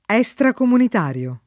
estracomunitario → extracomunitario
vai all'elenco alfabetico delle voci ingrandisci il carattere 100% rimpicciolisci il carattere stampa invia tramite posta elettronica codividi su Facebook estracomunitario [ HS trakomunit # r L o ] → extracomunitario